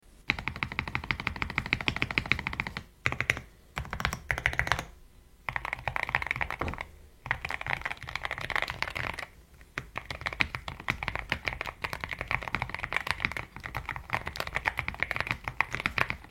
A mechanical keyboard’s sound profile varies dramatically, ranging from a deep, satisfying “thock” (often heard with popular switches like the Akko Rosewood Switch, known for its muted acoustics) to a crisp “clack” or distinct “click”(like Akko Creamy Cyan Switch).Shaped by switch type, keycaps, and board construction, this broad acoustic range allows users to tailor their typing sound from subtle to lively and expressive.
Akko Rosewood Switch (Linear)
Whats-the-difference-between-mechanical-keyboard-Akko-Rosewood-Switch-MonsGeek.mp3